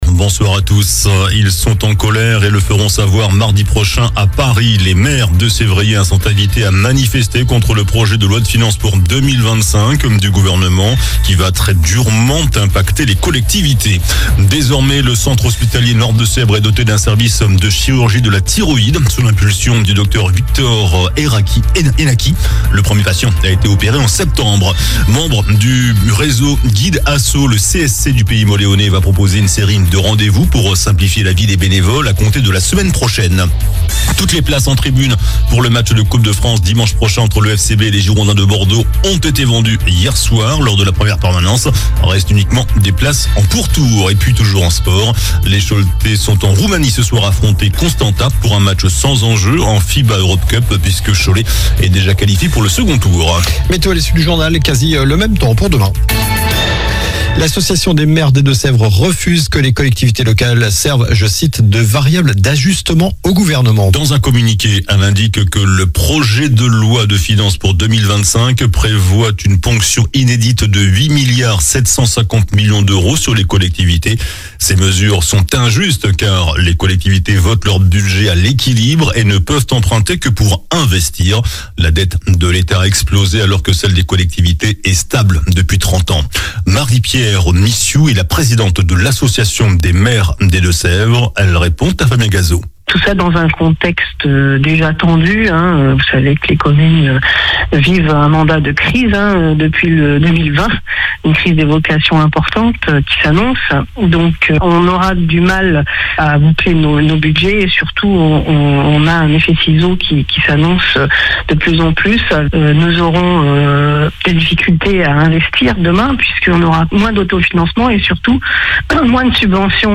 JOURNAL DU MERCREDI 13 NOVEMBRE ( SOIR )